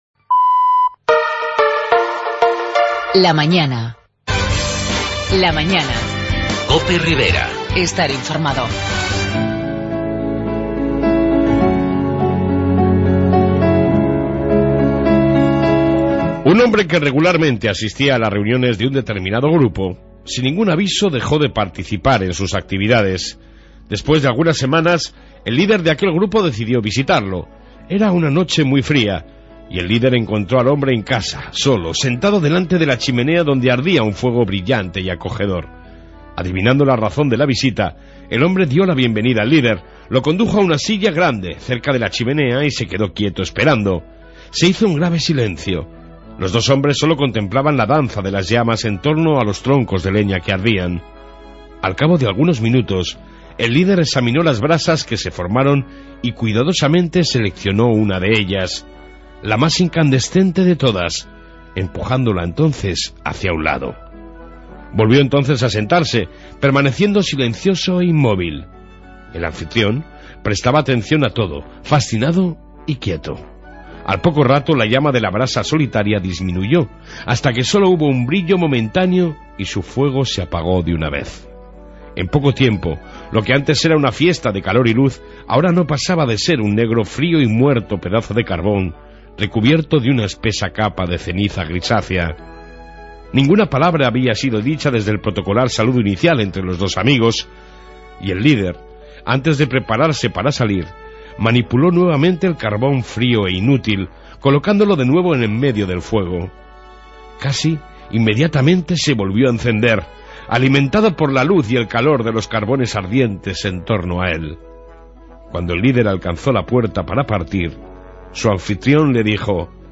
AUDIO: En esta 1 parte Reflexión diaria y amplia entrevista al fotógrafo